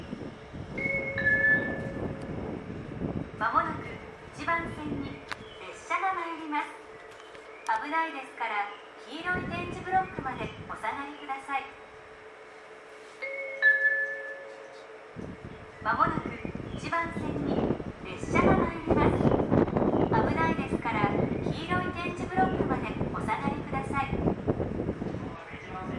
この駅では接近放送が設置されています。
１番線奥羽本線
接近放送普通　秋田行き接近放送です。